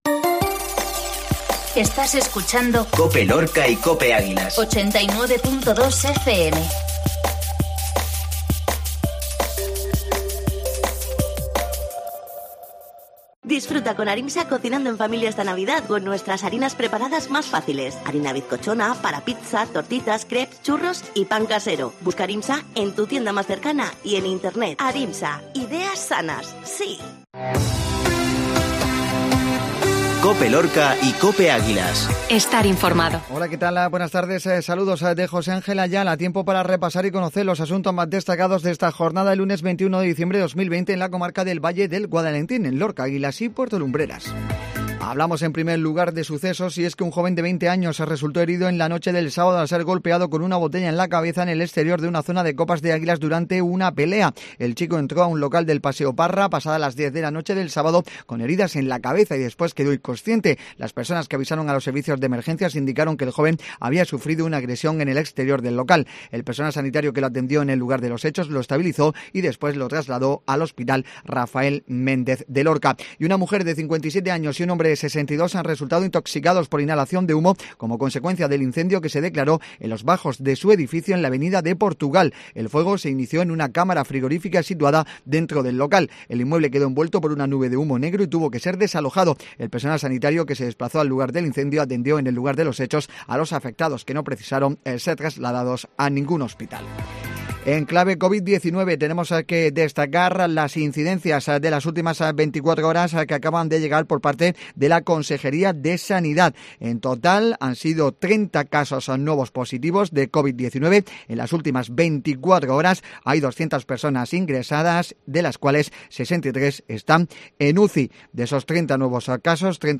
INFORMATIVO MEDIODÍA LUNES